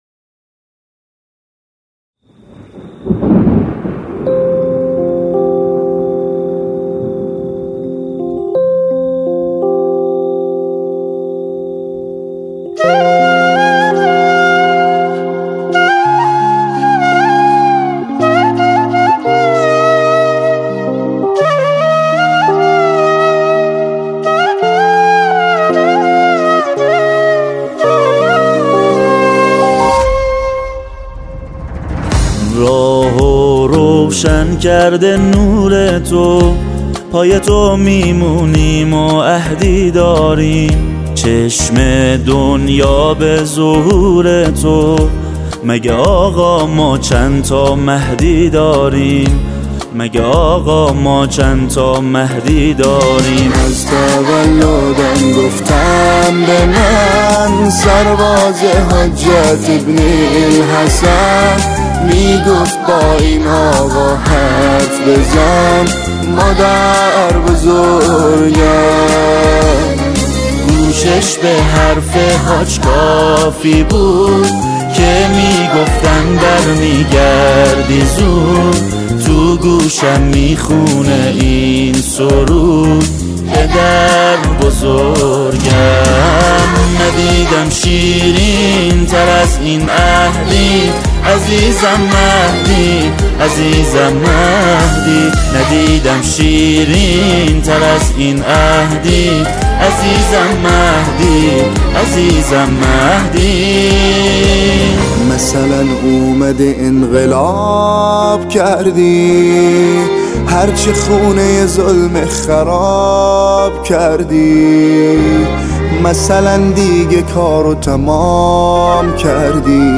ترکیب موسیقی حماسی و احساسی
ژانر: سرود